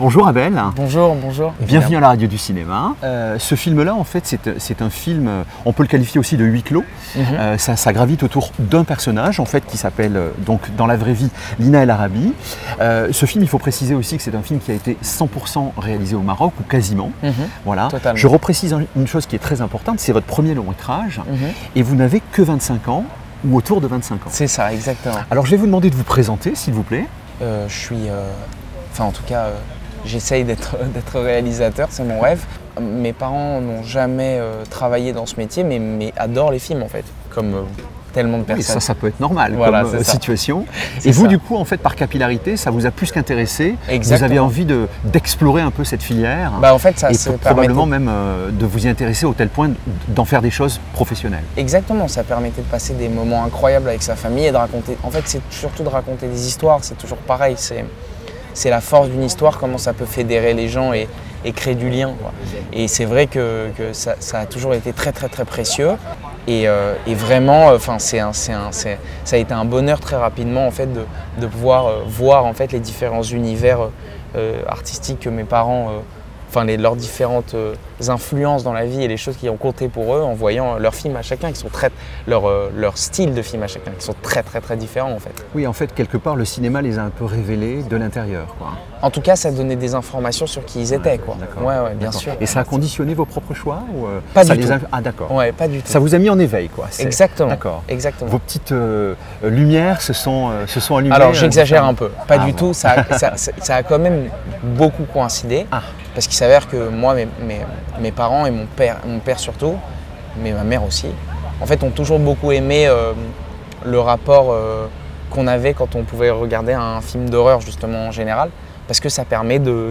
Dans une interview accordée